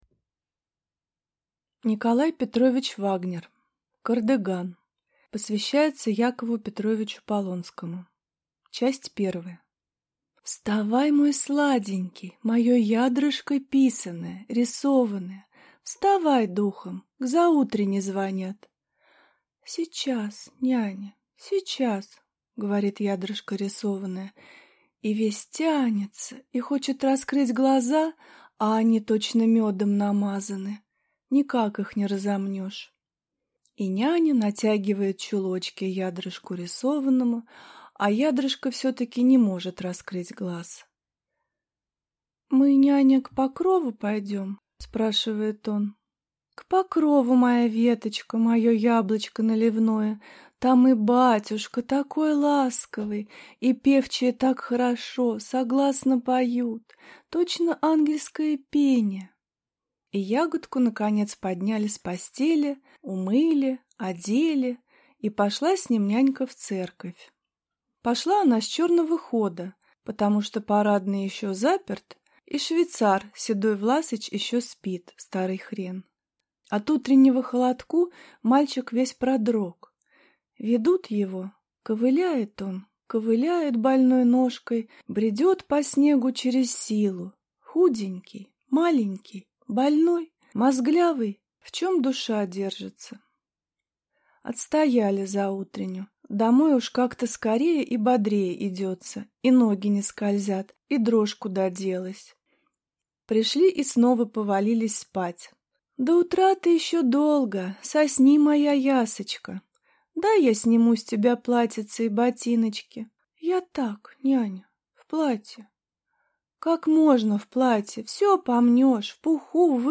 Аудиокнига Кардыган | Библиотека аудиокниг